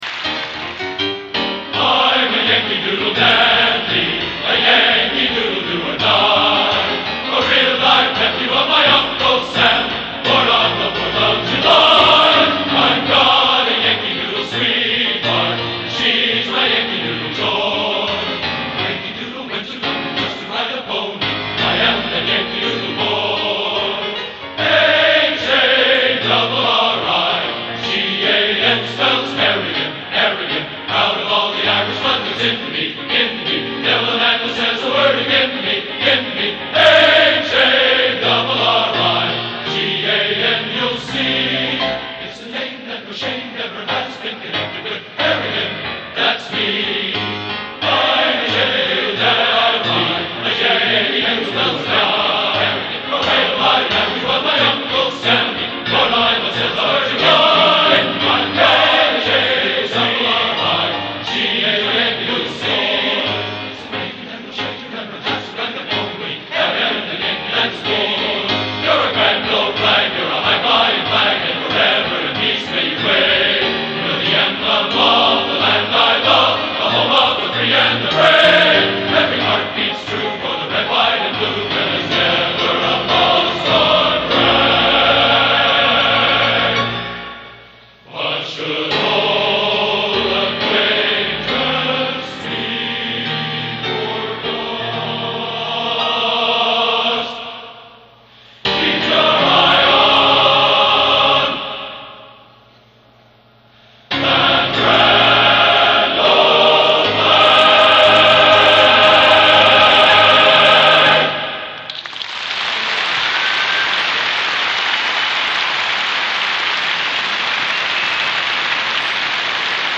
Genre: Patriotic | Type: